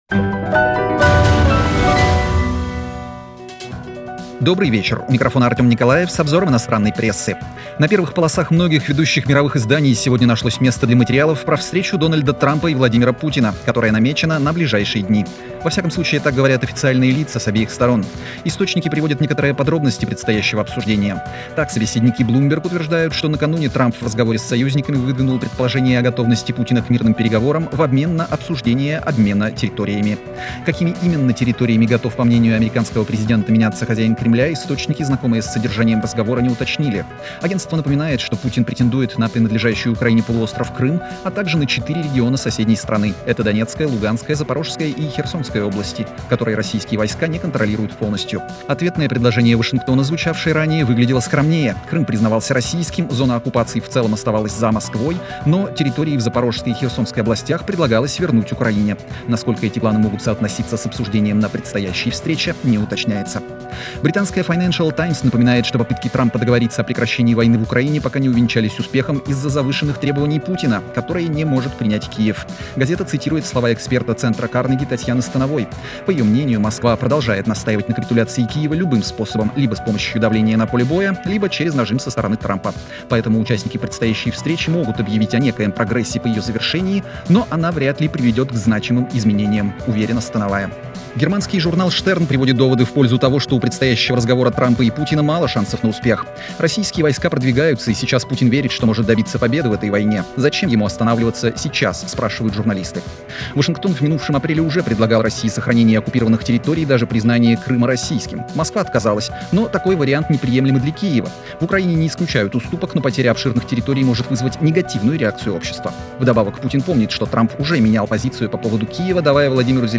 Обзор инопрессы 07.08.2025